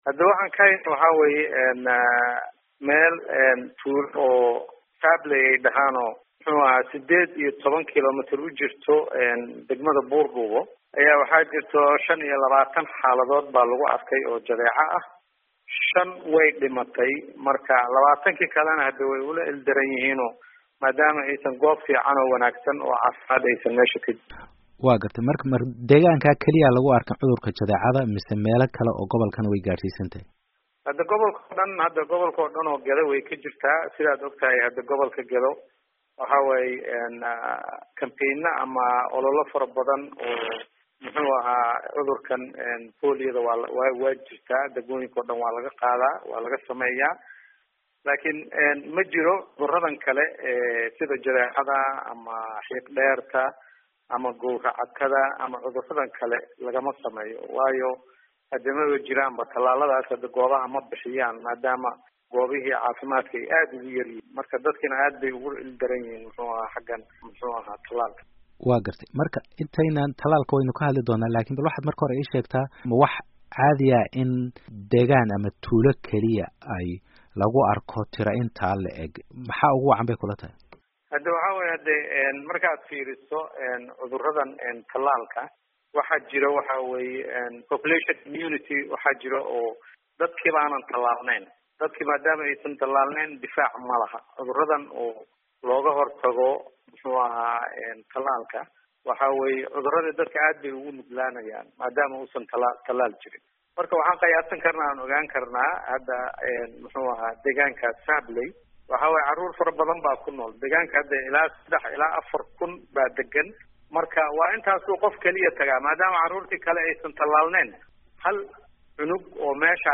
Waraysiga dad u dhintay xanuunka Jadeecada